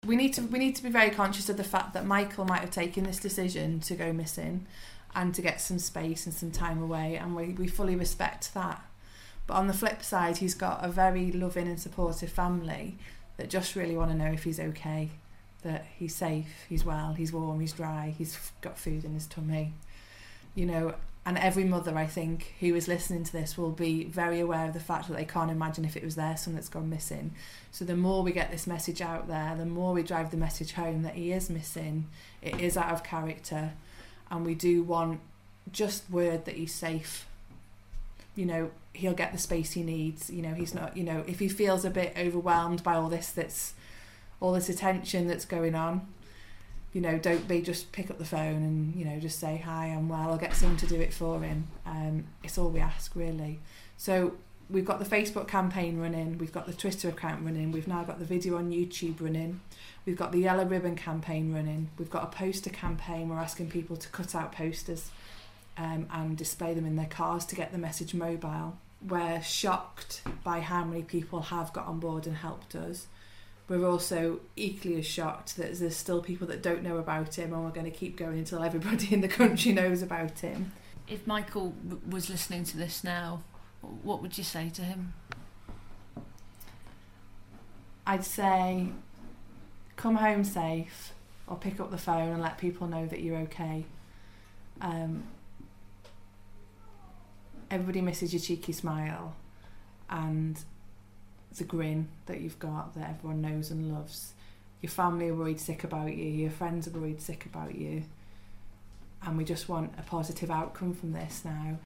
friend speaks to Radio City